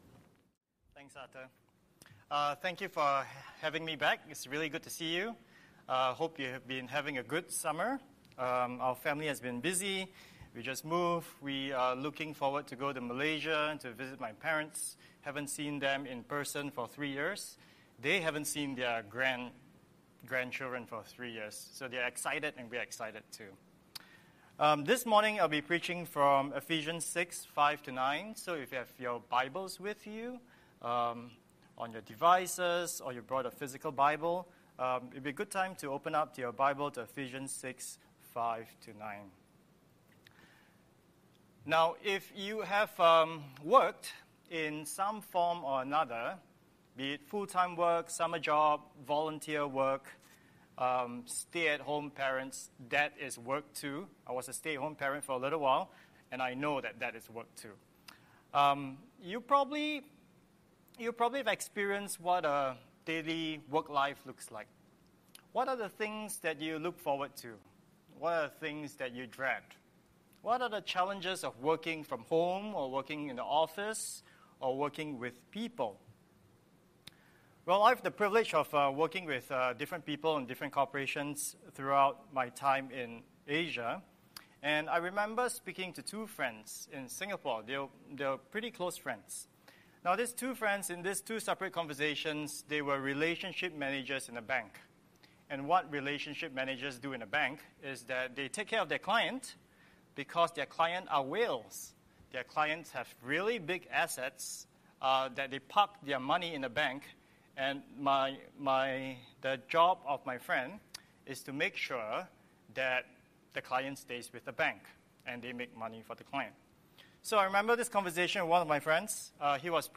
Scripture: Ephesians 6:5–9 Series: Sunday Sermon